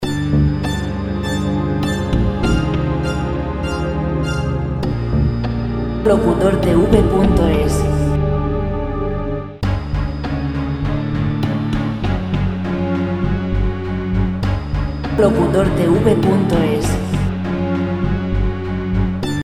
musica clasica gratis sin copyright